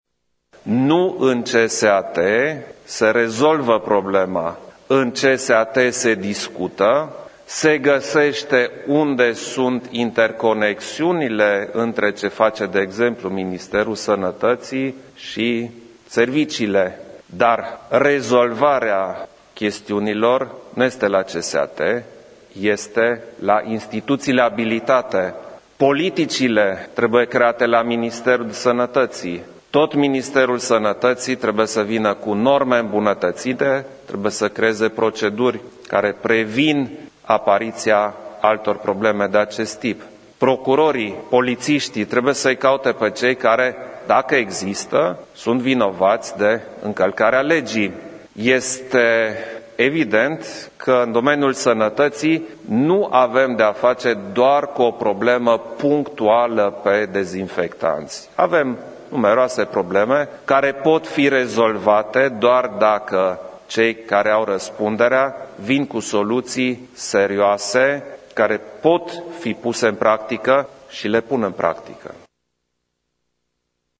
Șeful statului a declarat că a cerut pentru această lămurire în CSAT materiale de la ministerul Sănătăţii, dar a subliniat că nu în CSAT se rezolvă problema sănătăţii:
Iohannis-sanatate.mp3